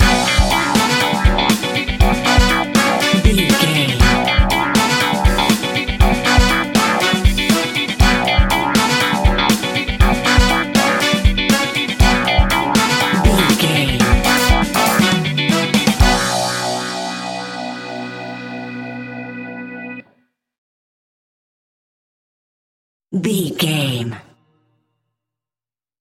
Aeolian/Minor
groovy
uplifting
bouncy
drums
electric guitar
percussion
bass guitar
horns
funky house
disco house
electro funk
upbeat
synth leads
Synth pads
synth bass
drum machines